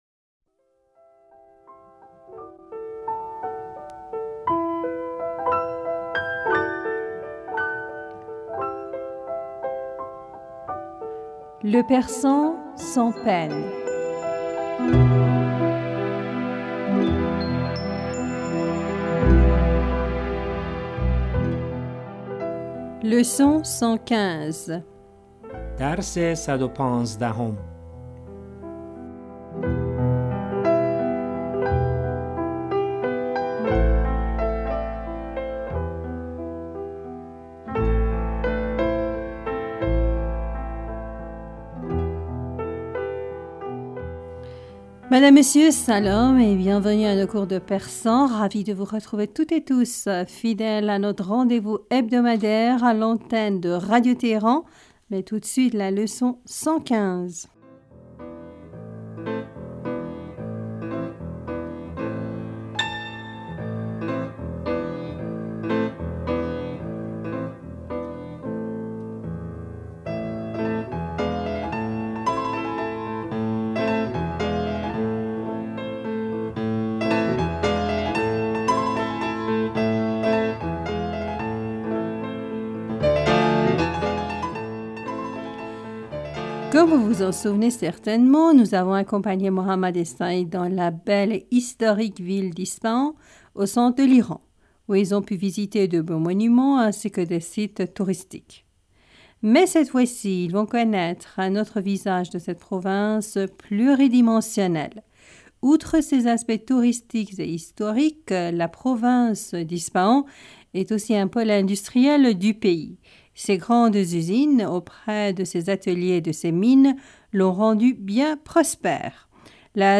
Madame, Monsieur Salam et bienvenue à nos cours de persan.
Ecoutez et répétez après nous.